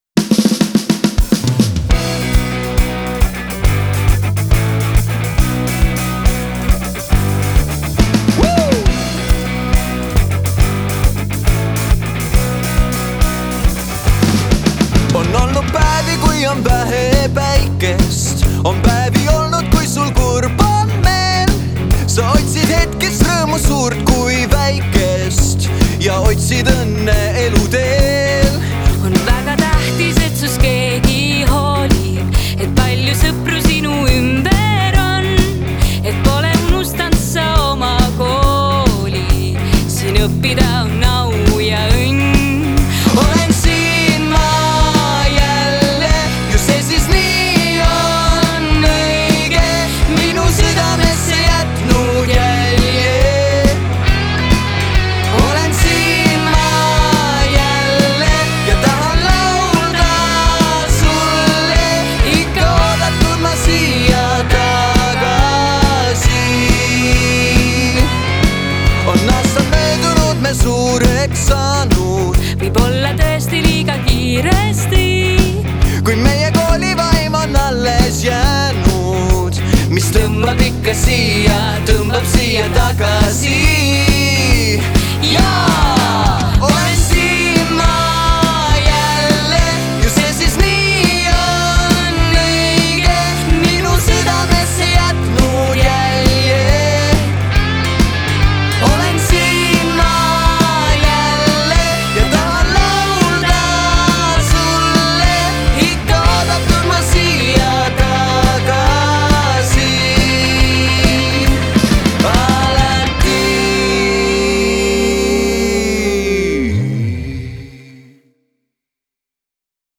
Koolilaul 50. juubel.wav